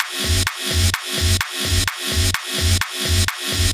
VEH1 Fx Loops 128 BPM
VEH1 FX Loop - 12.wav